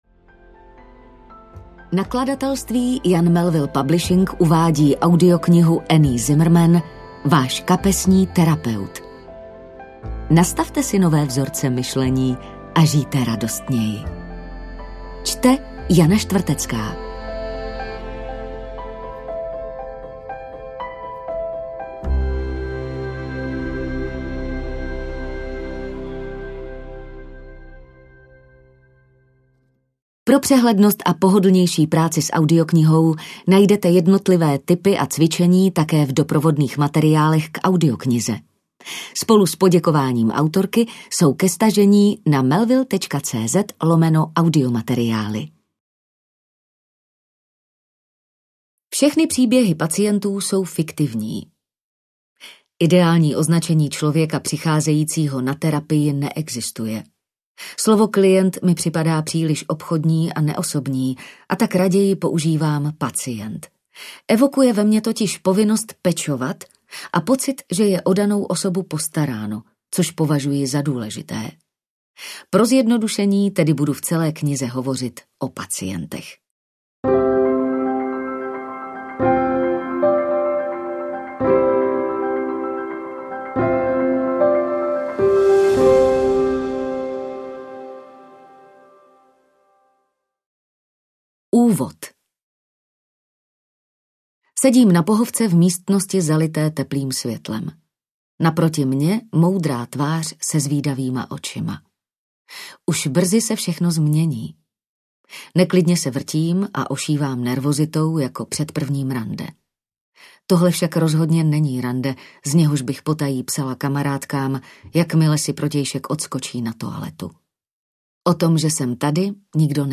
Váš kapesní terapeut audiokniha
Ukázka z knihy
vas-kapesni-terapeut-audiokniha